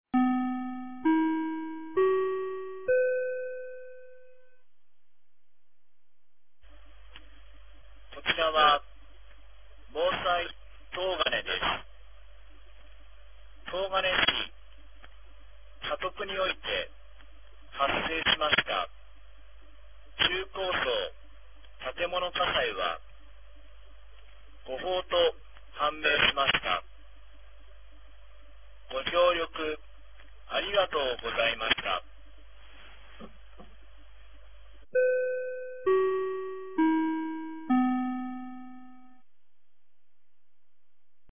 2025年01月27日 15時16分に、東金市より防災行政無線の放送を行いました。